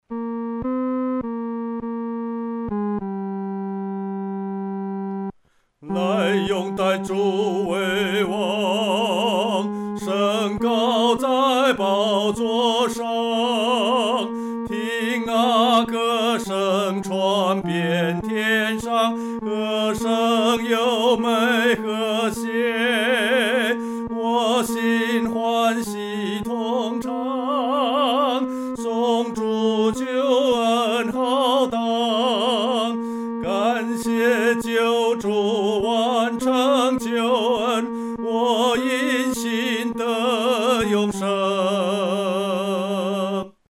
独唱（第三声）